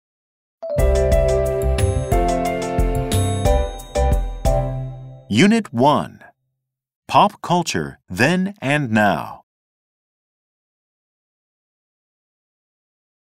2020年5月13日(水) 英語科　教科書予習用　リスニング教材について